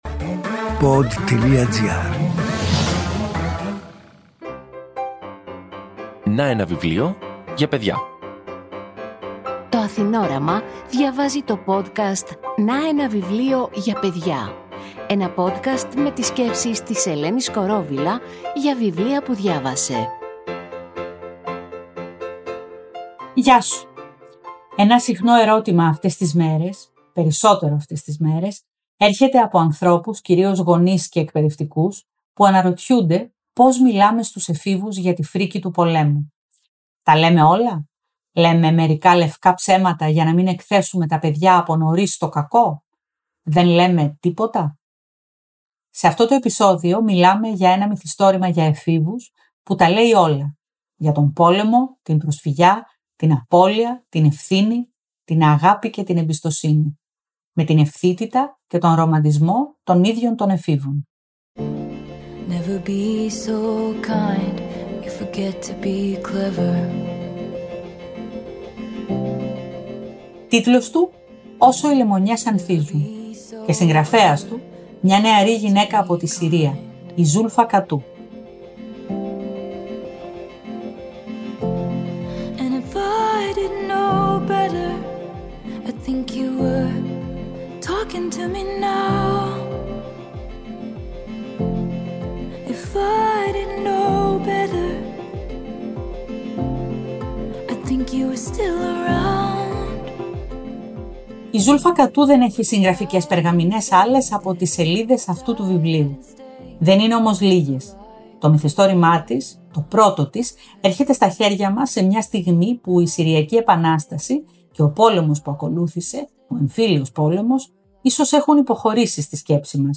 Αποσπάσματα από το βιβλίο διαβάζει